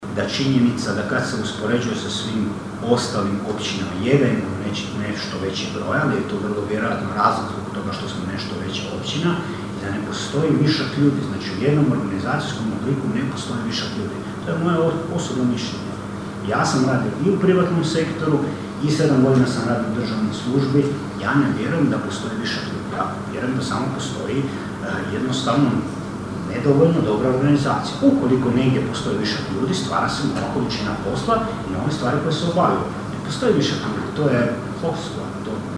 Prijedlog Odluke o II. izmjenama i dopunama Odluke o unutarnjem ustrojstvu i djelokrugu općinske uprave izazvao je žustru raspravu na ovotjednoj sjednici Općinskog vijeća Kršana.
O višku zaposlenih, predsjednik Vijeća Ivan Zambon: (